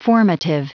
Prononciation du mot formative en anglais (fichier audio)
Prononciation du mot : formative